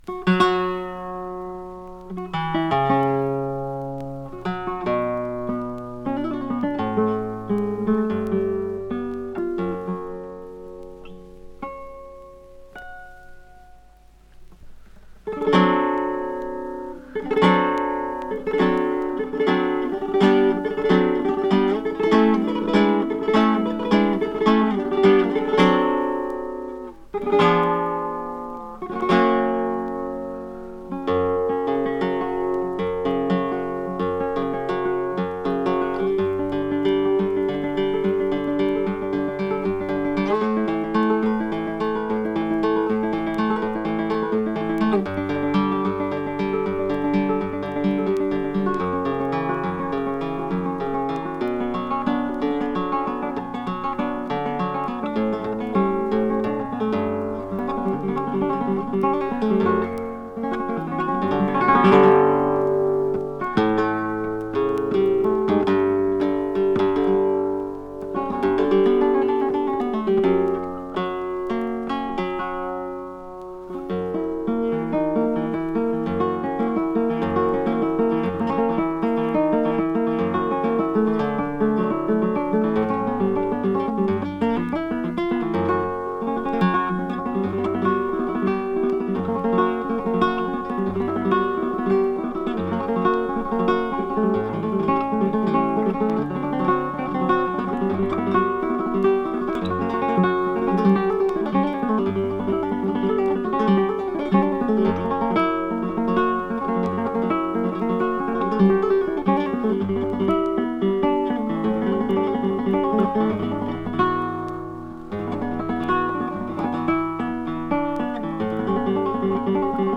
(malagueña)